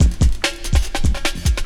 16 LOOP09 -R.wav